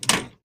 break.ogg